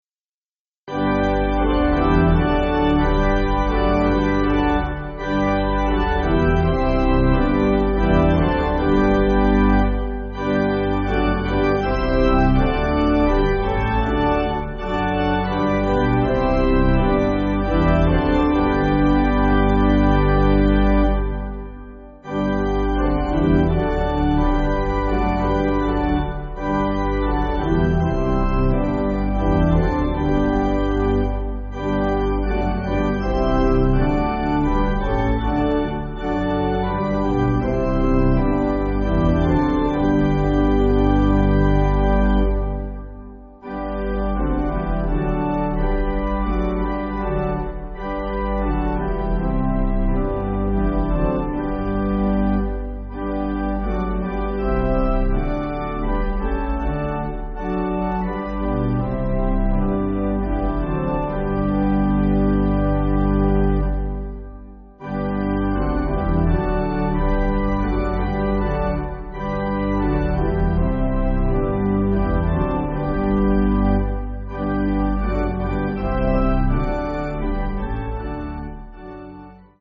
Organ
(CM)   6/G